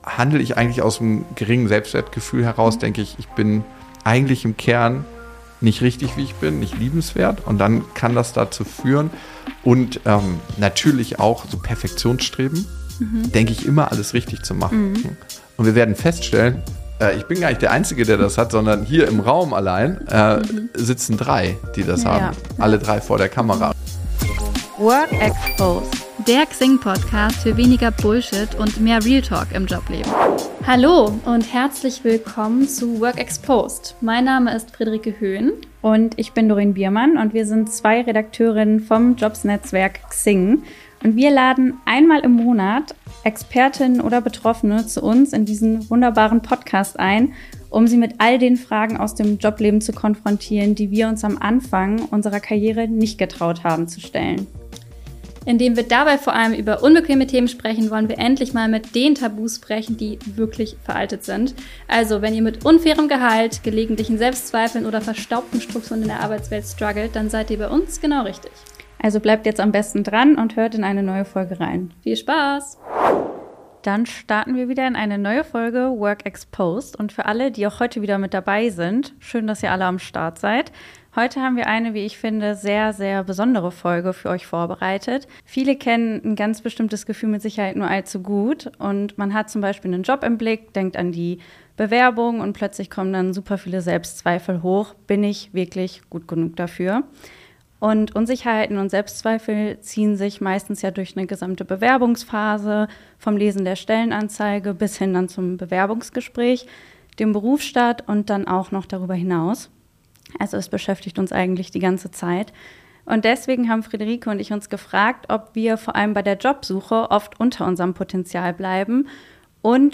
In dieser Folge sprechen wir mit Psychologe und Podcaster